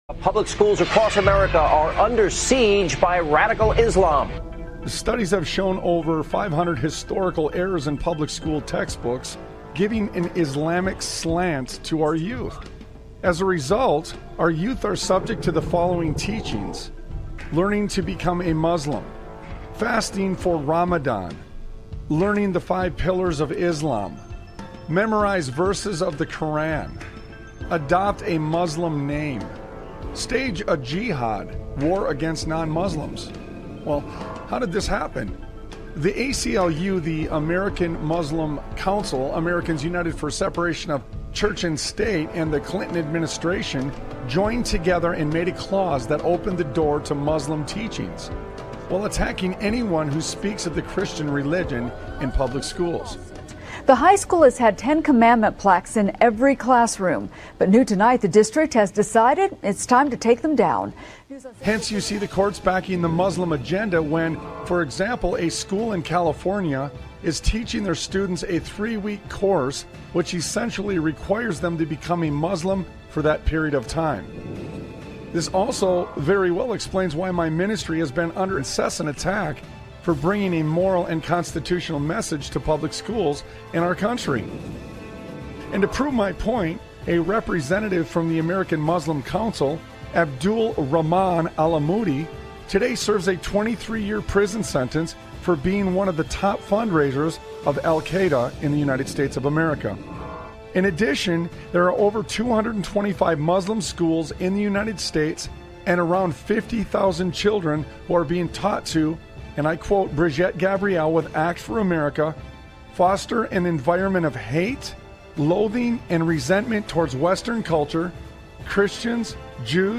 Talk Show Episode, Audio Podcast, Sons of Liberty Radio and Consider This You That Forget God on , show guests , about Consider This You That Forget God, categorized as Education,History,Military,News,Politics & Government,Religion,Christianity,Society and Culture,Theory & Conspiracy